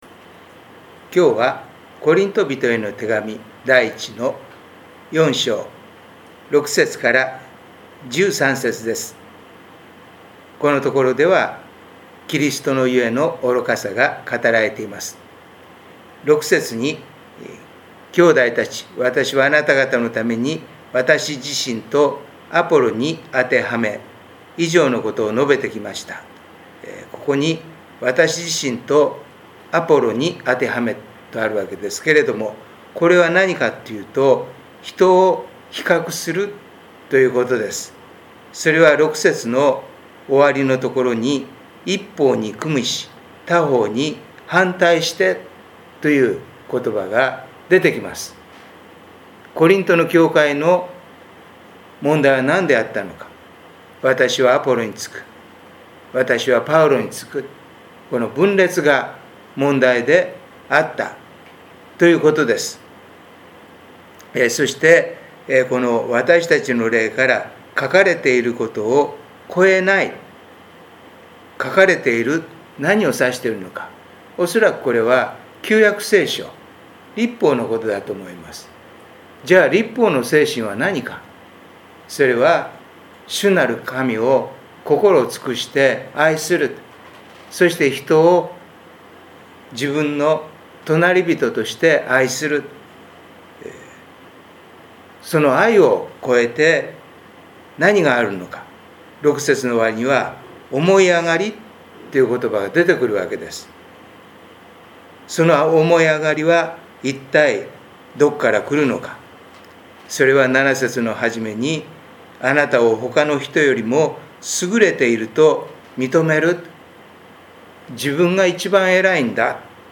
第四主日伝道礼拝
音声メッセージです。